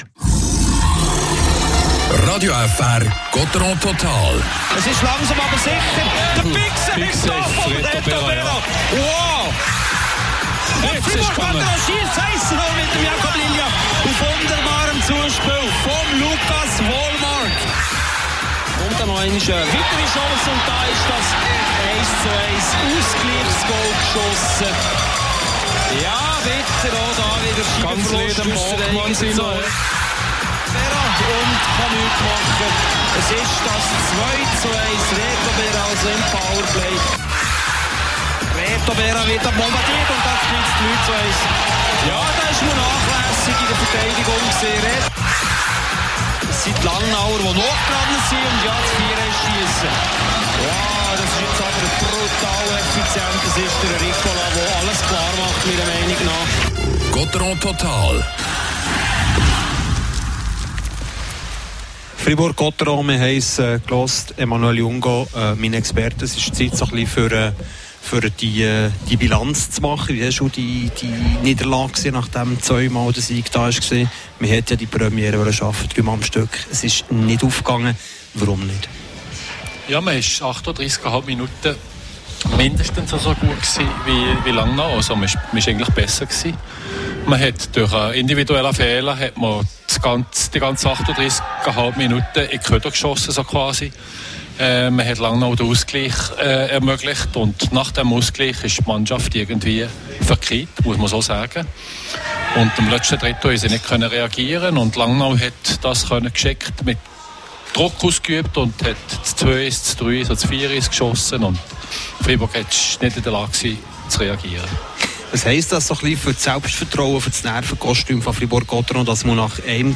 Zum dritten Mal in dieser Saison verlieren die Freiburger gegen die Tigers. Die Live-Highlights, Analyse und die Interviews